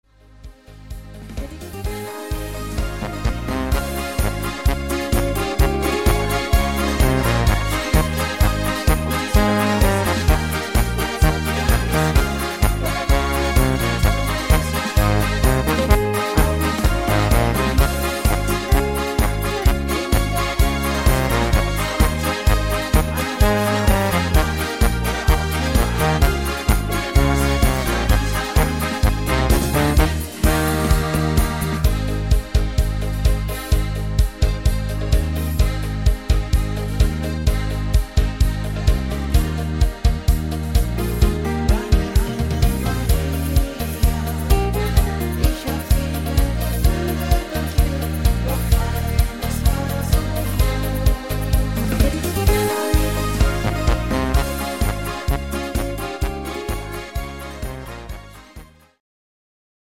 Rhythmus  8 Beat